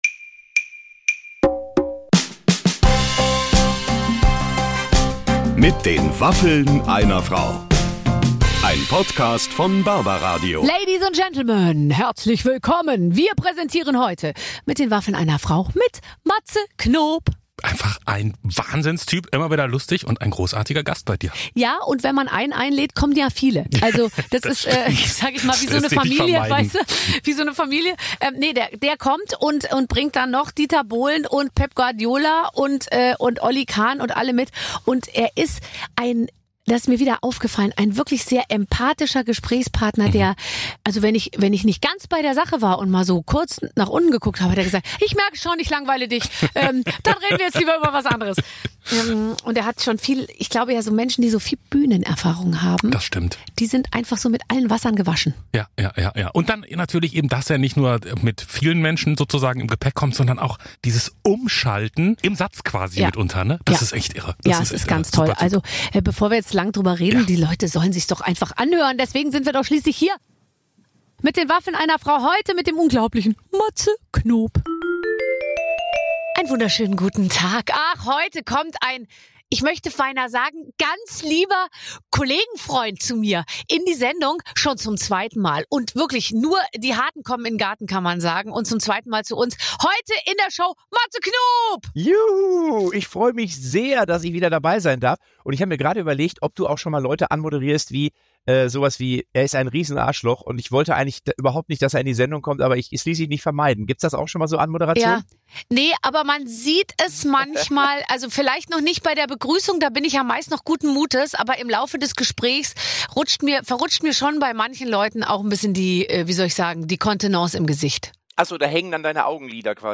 Jedes Wochenende trifft Barbara Schöneberger Prominente aus Musik, Fernsehen, Sport und Showbiz. Der Unterschied zu allen anderen Talkshows: Barbara Schöneberger hat Waffeln gebacken.